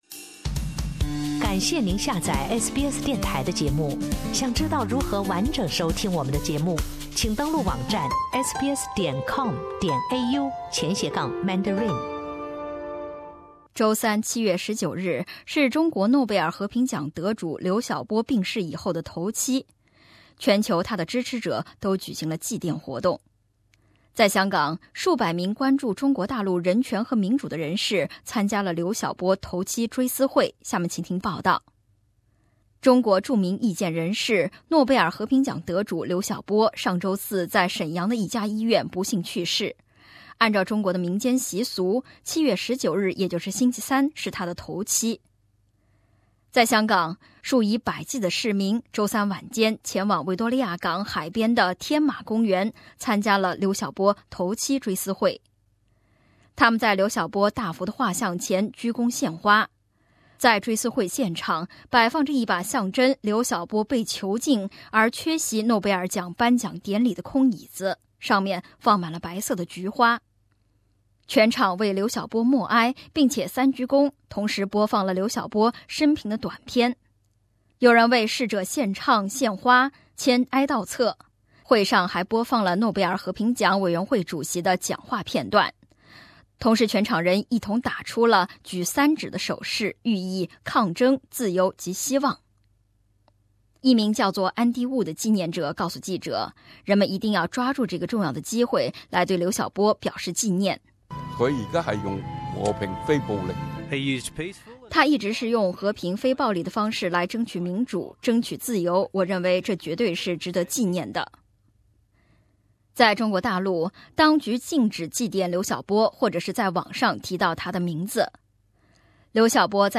在香港，数百名关注中国大陆人权和民主的人士参加了刘晓波头七追思会。请听详细报道。